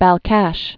(băl-kăsh, bäl-käsh, -äsh), Lake